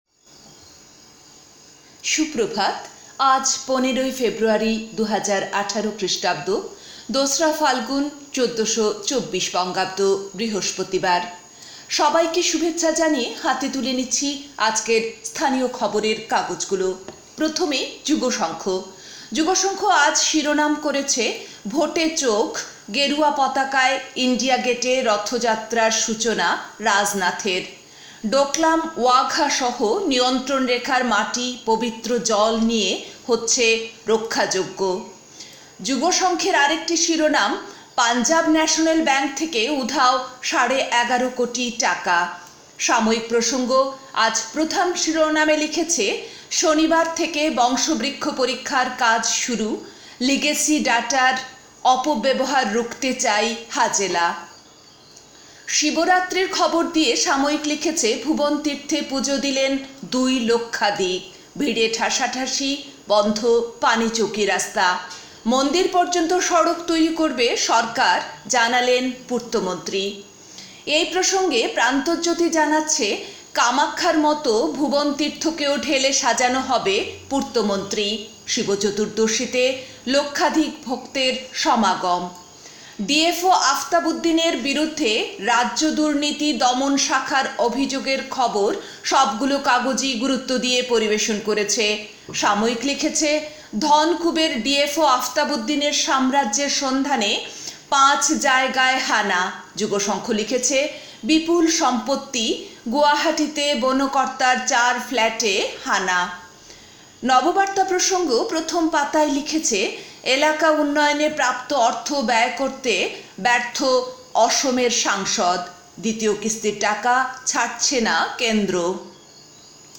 A quick bulletin with all top news headlines.